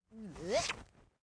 Npc Catslurpy Sound Effect
Download a high-quality npc catslurpy sound effect.
npc-catslurpy.mp3